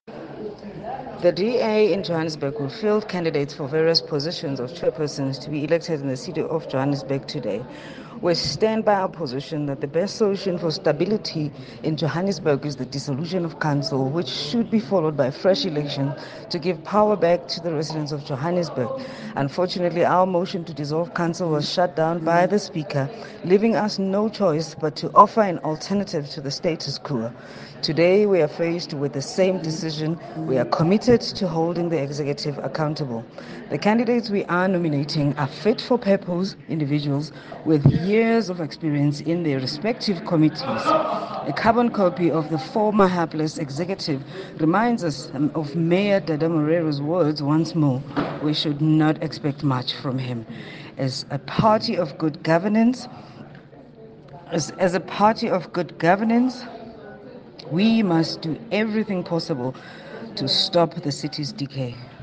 Note to Editors: Please find an English Soundbite by Cllr Nonhlanhla Sifumba, DA Johannesburg Caucus Chairperson,